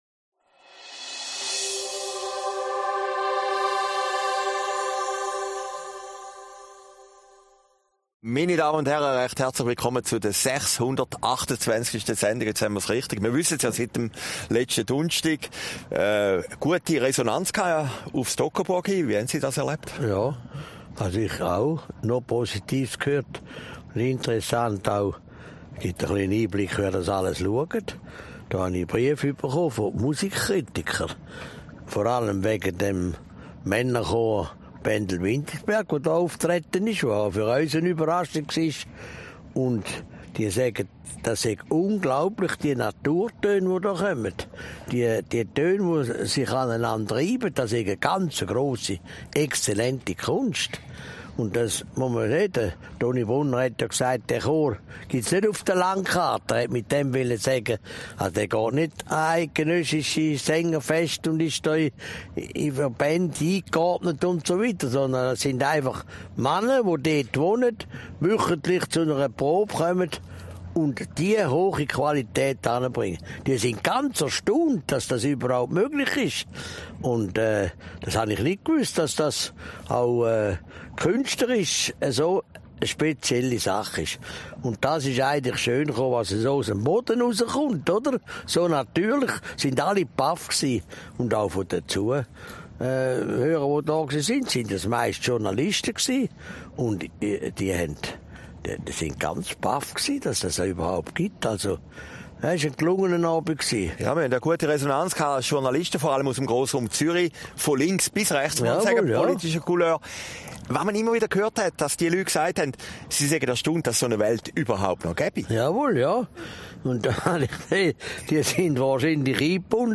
Aufgezeichnet in Herrliberg, 13. September 2019